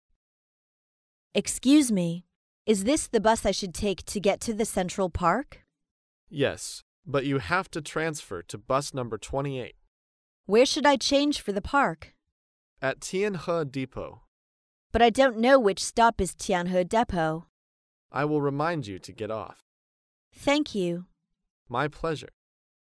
在线英语听力室高频英语口语对话 第402期:询问搭乘公共汽车(2)的听力文件下载,《高频英语口语对话》栏目包含了日常生活中经常使用的英语情景对话，是学习英语口语，能够帮助英语爱好者在听英语对话的过程中，积累英语口语习语知识，提高英语听说水平，并通过栏目中的中英文字幕和音频MP3文件，提高英语语感。